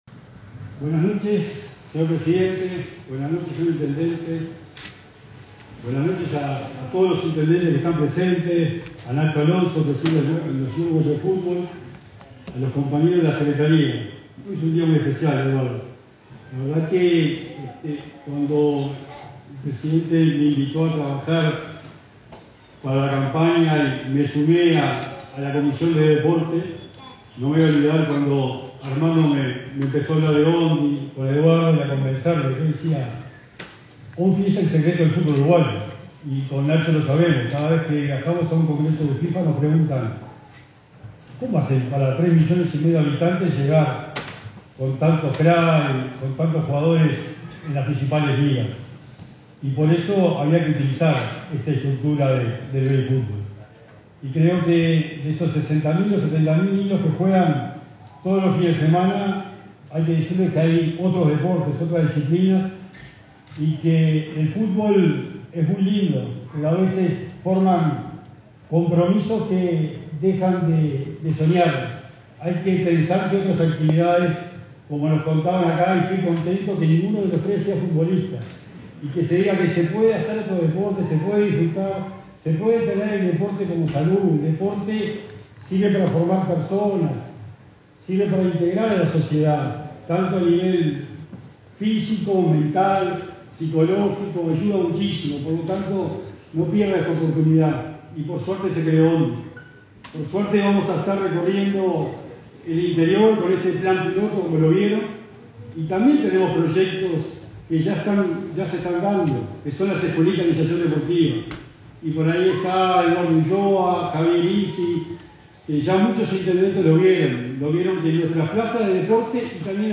Declaraciones del secretario nacional del Deporte, Sebastián Bauzá
Este 19 de agosto se efectuó el lanzamiento del programa Organización Nacional del Deporte Infantil en Flores. Sebastián Bauzá participó en el evento.